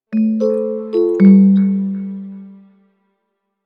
04864 notification music box
bell ding mechanical-instrument music-box notification ring signal toy sound effect free sound royalty free Music